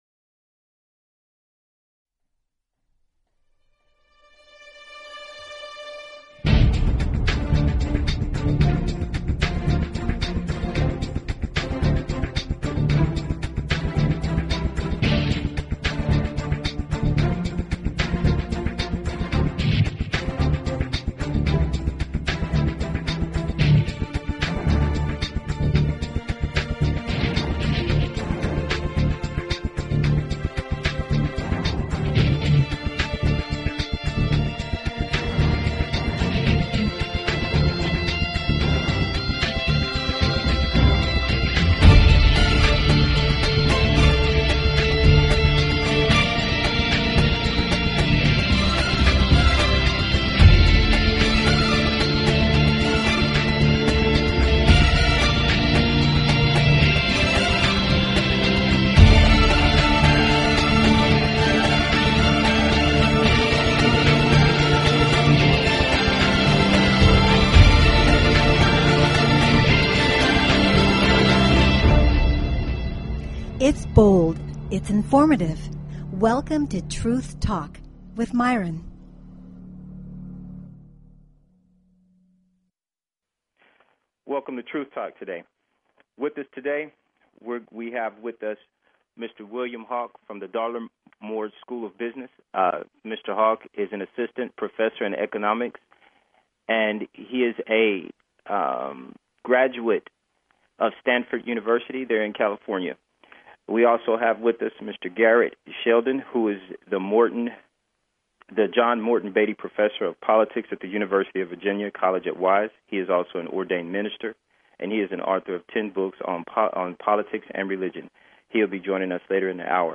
Talk Show Episode, Audio Podcast, Truth_Talk and Courtesy of BBS Radio on , show guests , about , categorized as